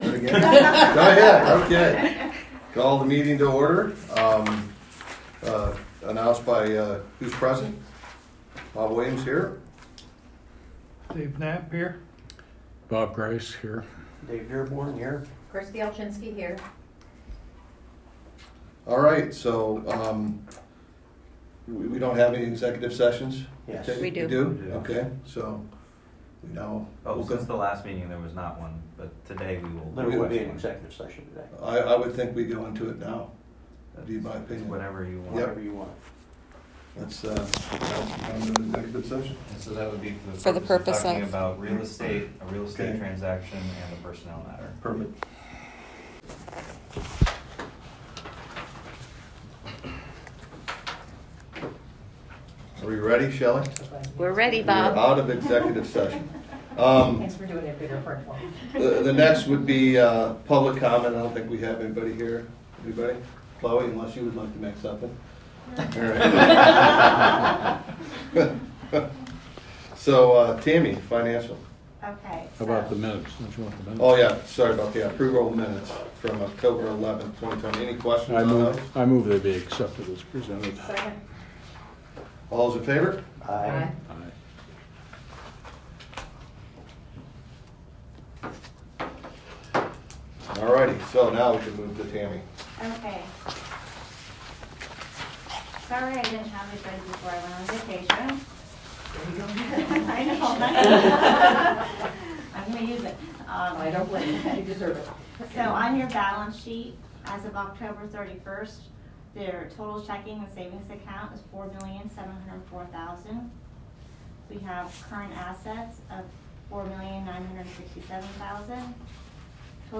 Meeting Audio
11.8.22-Regular-Meeting.m4a